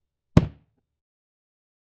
Hit 01